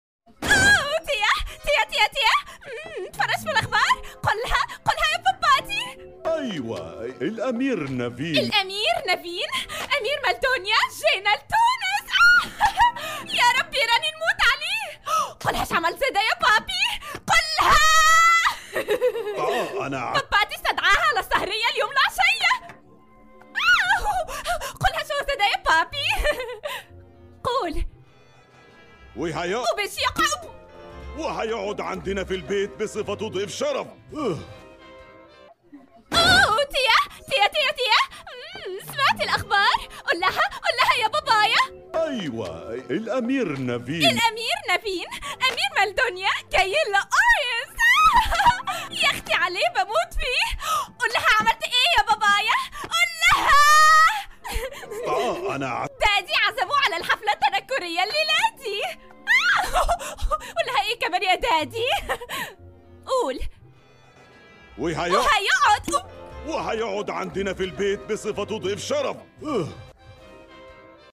تحدي اللهجات بين اللهجة التونسية